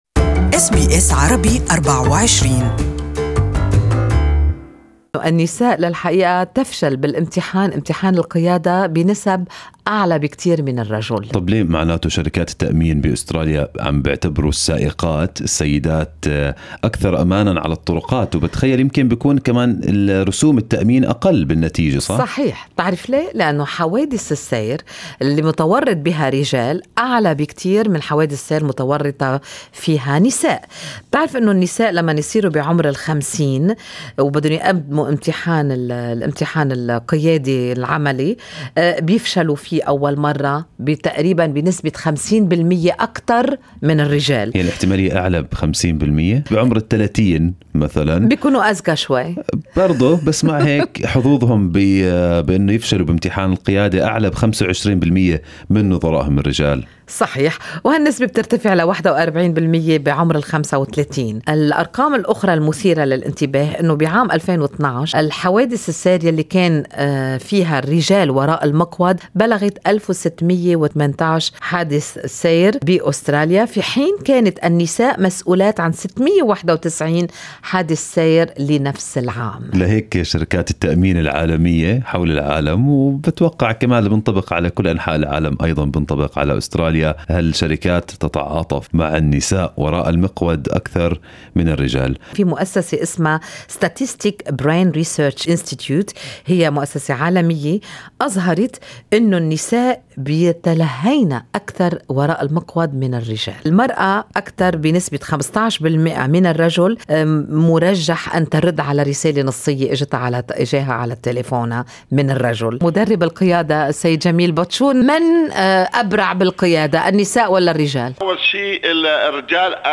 Driving instructor